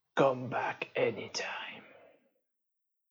Close Vender Dialog Line.wav